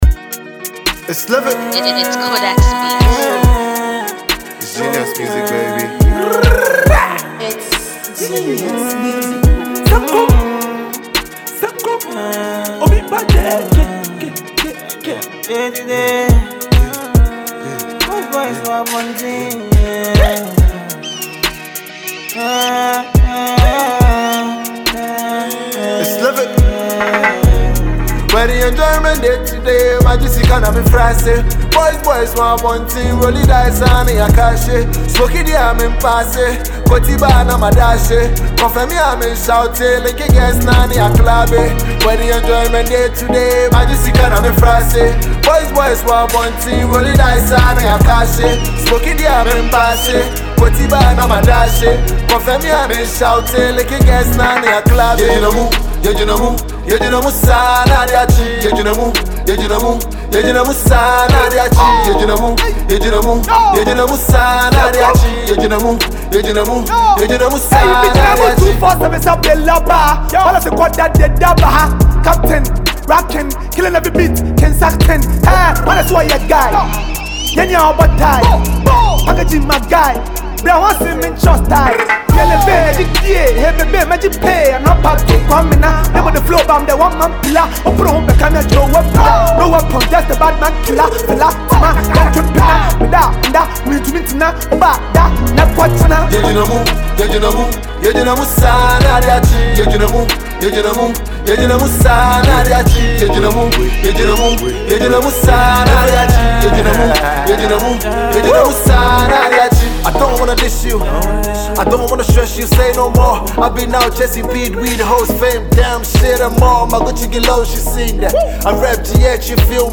a Ghanaian trapper
This is a banger all day.